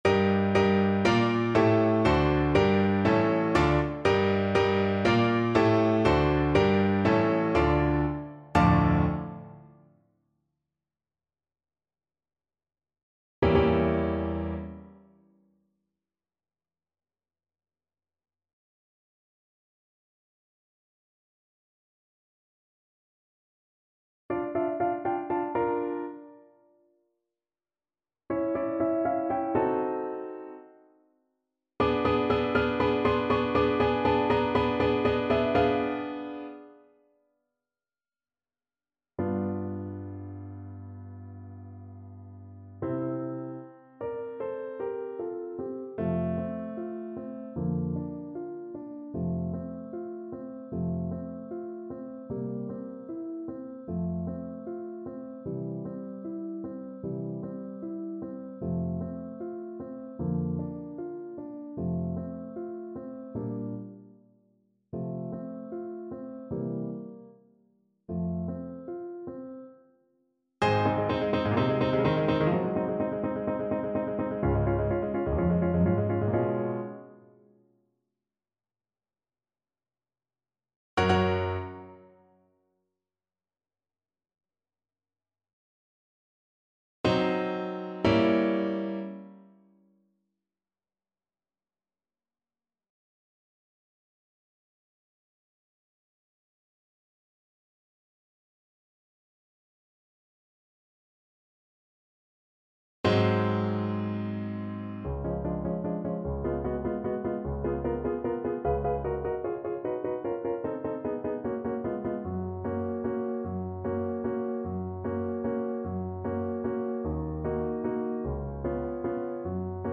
Allegro (View more music marked Allegro)
4/4 (View more 4/4 Music)
Classical (View more Classical Tenor Voice Music)